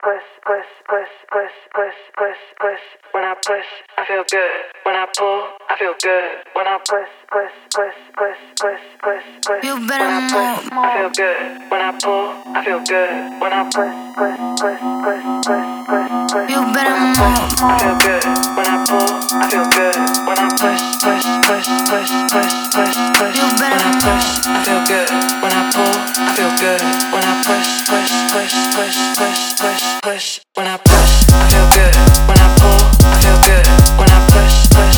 Жанр: Танцевальные / Электроника